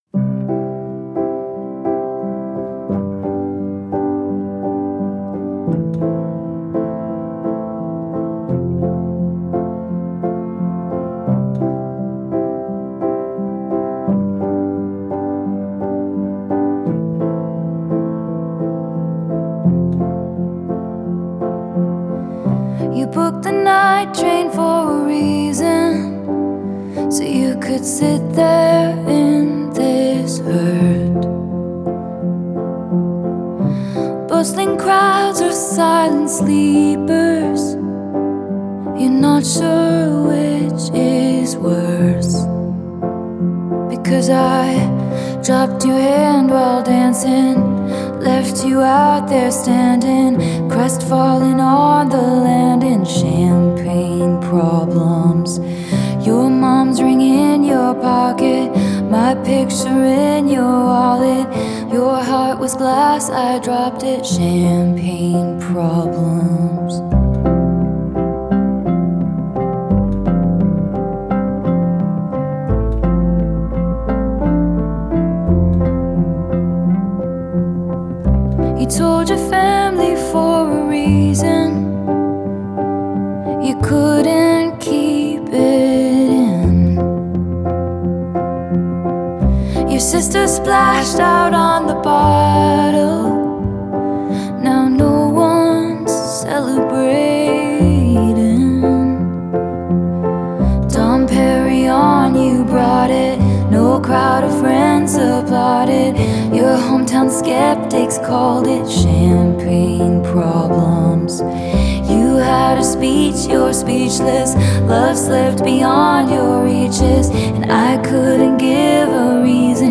выполненный в жанре инди-фолк.
акцентируя внимание на вокале и пианино.